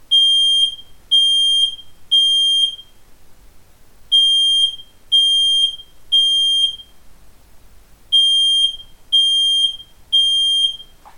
• Lautstärke im Test: 99,2 dBA
jalo-lento-10-rauchmelder-alarm.mp3